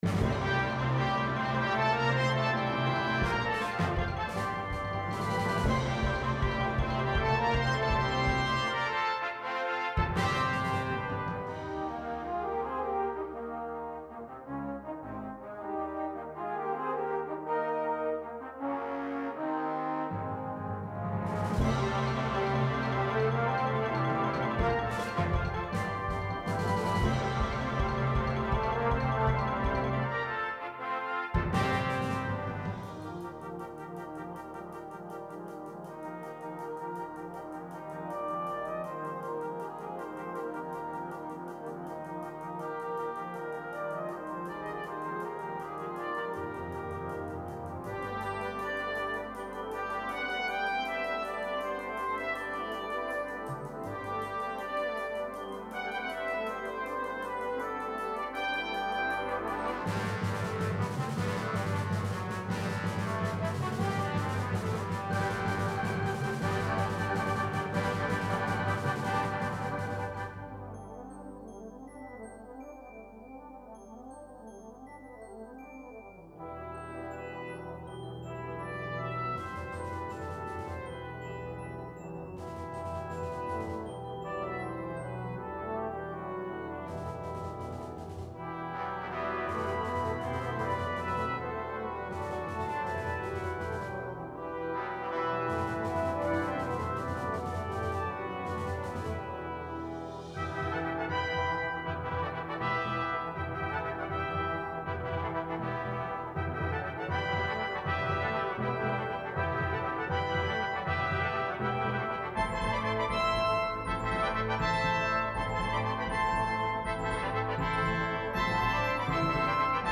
Brass-Band
et saura ouvrir vos concerts avec énergie !